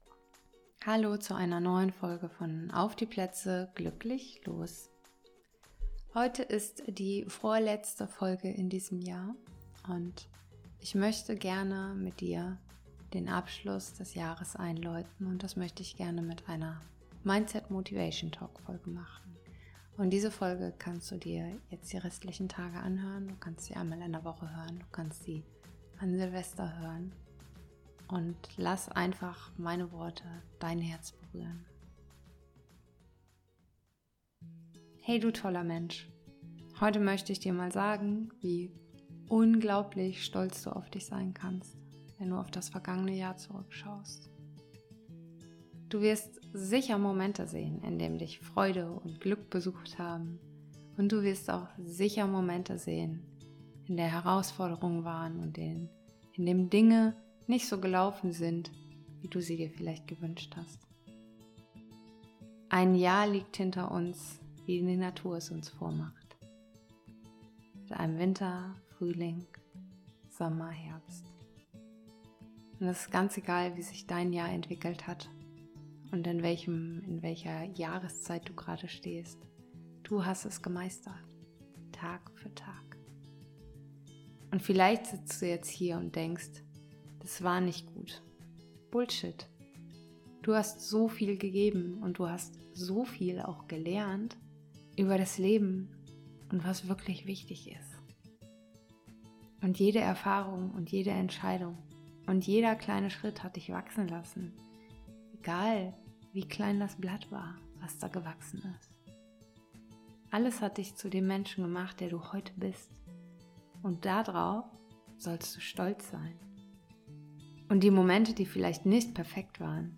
In dieser Mindset Motivation Talk Folge feiern wir deine Fortschritte, die großen und kleinen Erfolge sowie die Herausforderungen, die du gemeistert hast.
Mindset_Motivation_Talk_Folge_neues_Jahr.mp3